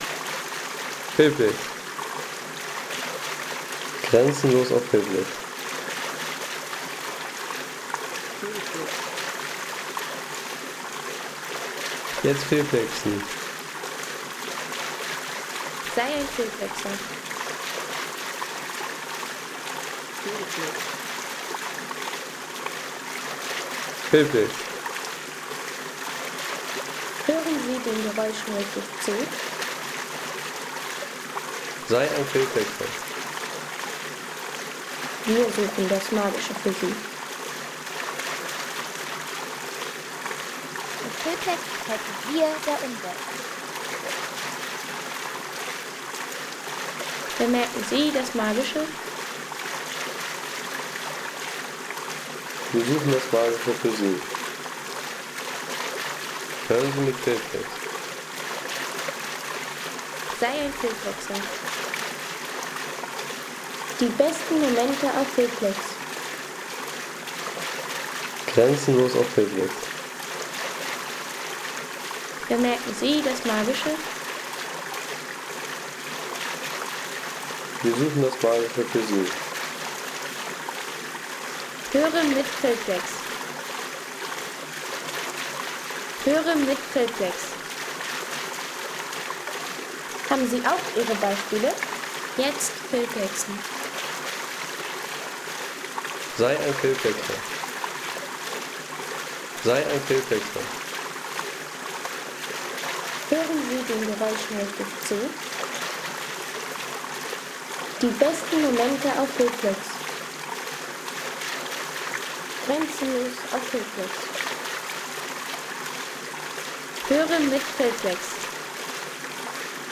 Wasserrad-Männchen